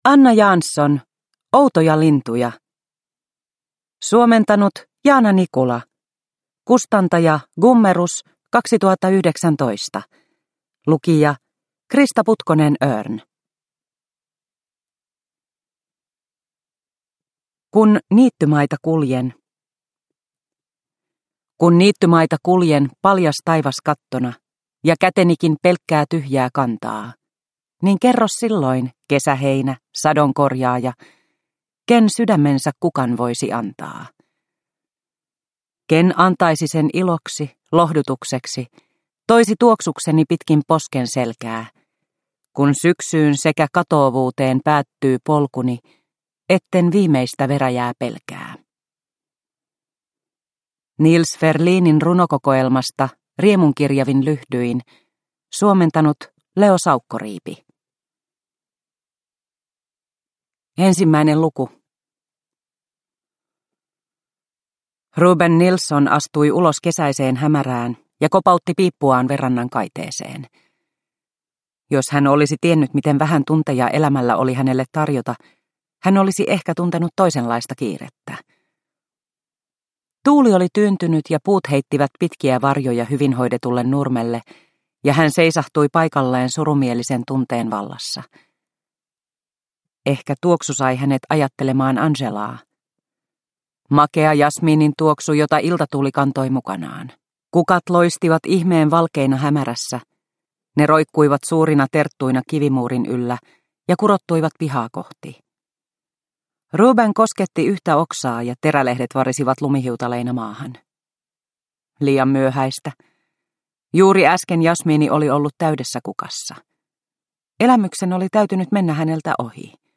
Outoja lintuja – Ljudbok – Laddas ner